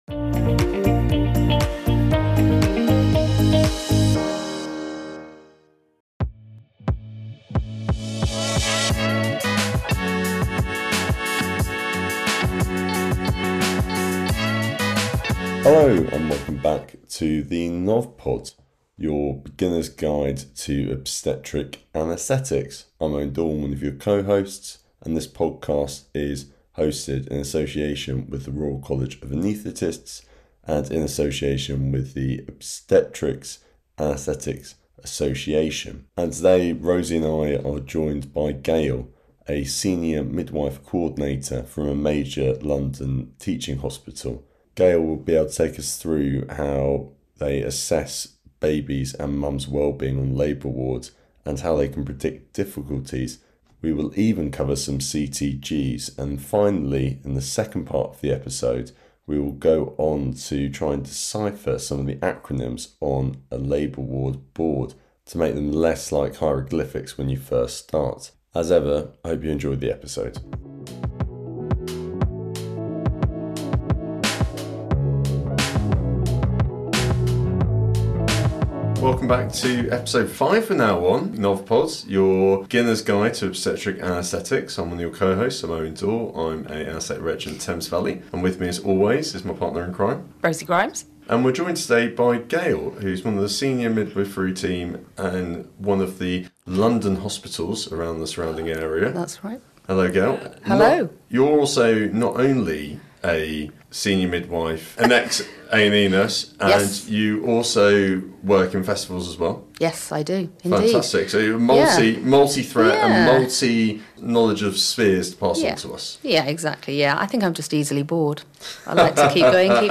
a midwife coordinator, to talk through how the labour ward actually works.